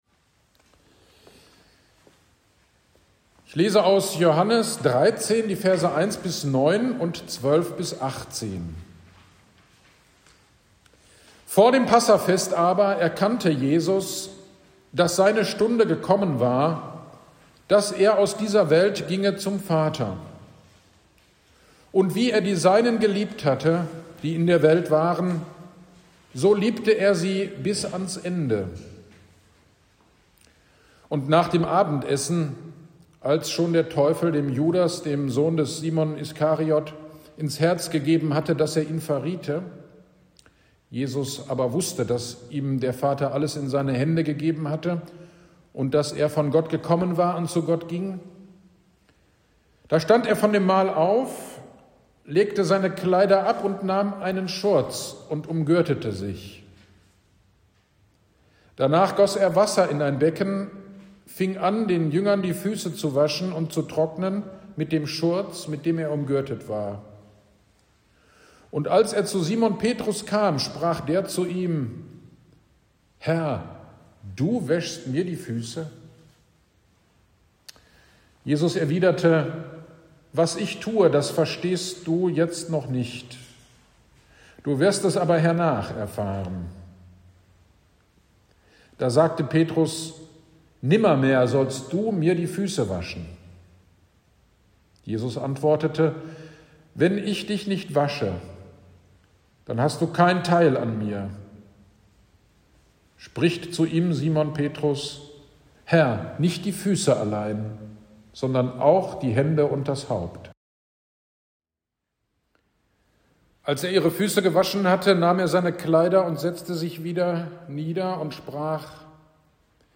AGD am 02.04.26 (Gründonnerstag) Predigt zu Johannes 13, 21-30